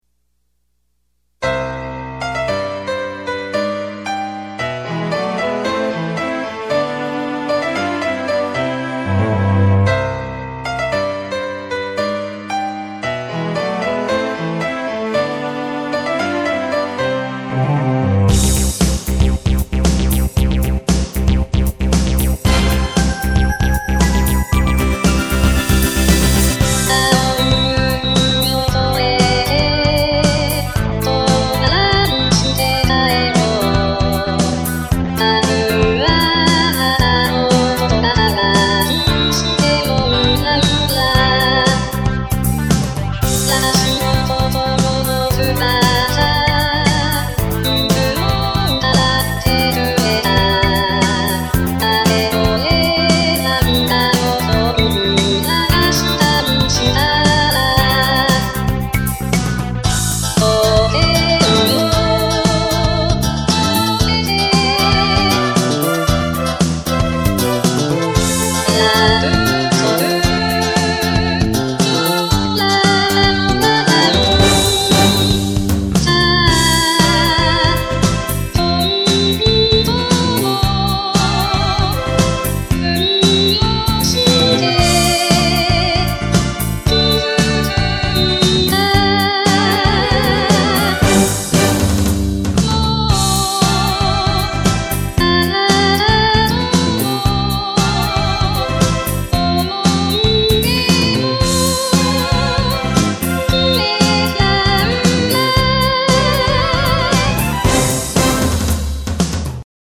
ワンコーラス　 ＭＰ３ファィル・・・1.94ＭＢ
ｓｏｎｇ :　ｙａｍａｈａ　ＰＬＧ-100ＳＧ